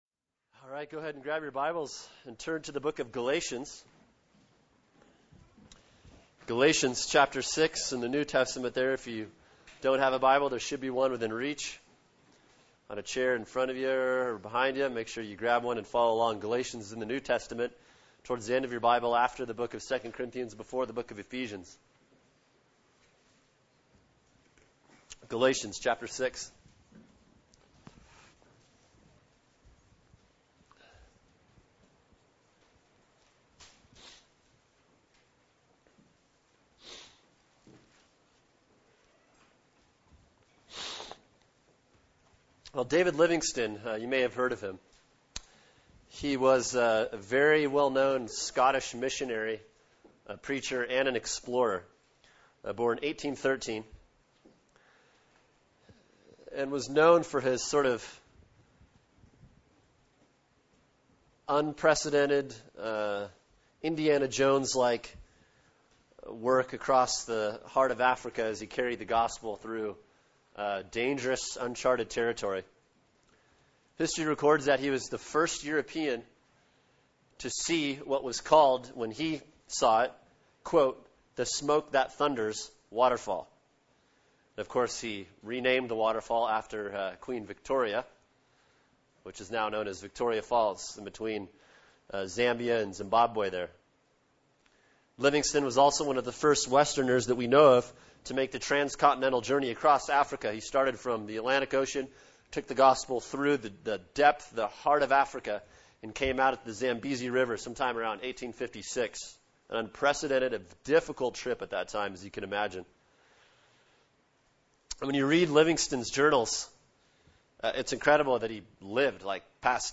[sermon] Galatians 6:17-18 “Badge of Honor” | Cornerstone Church - Jackson Hole